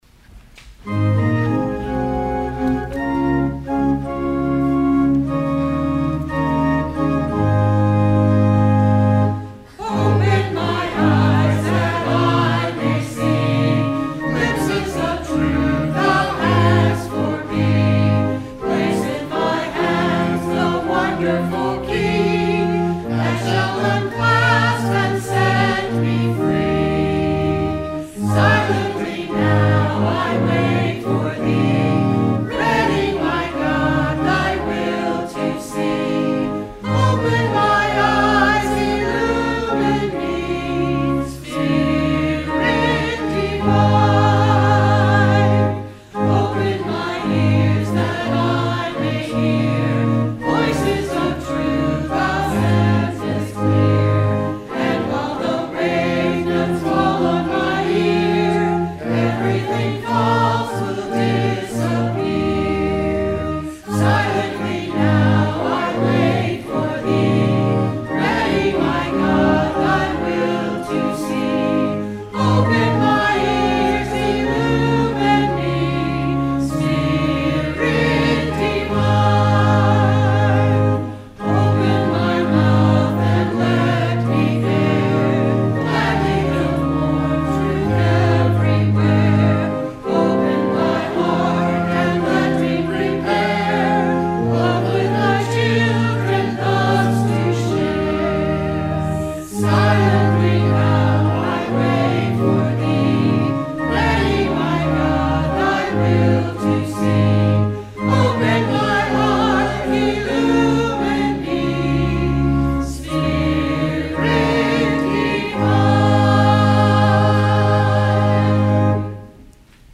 Sung by the Church and Choir